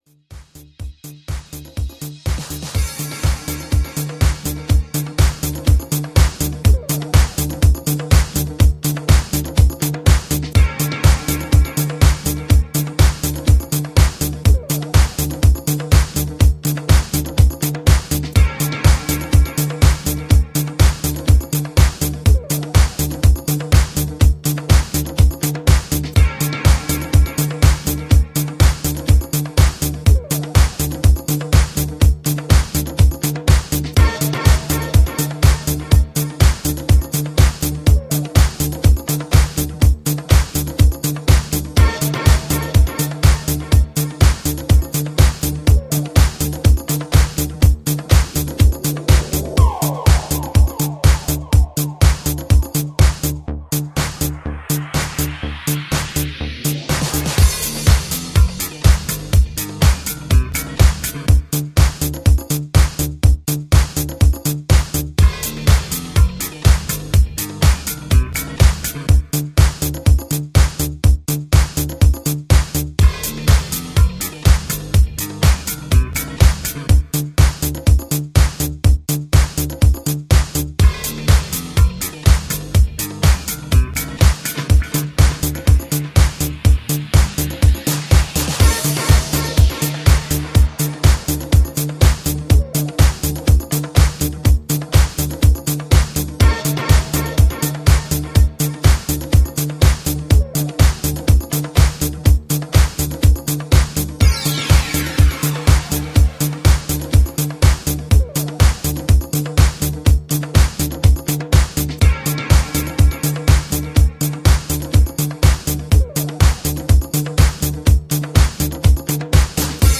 a minimal electro disco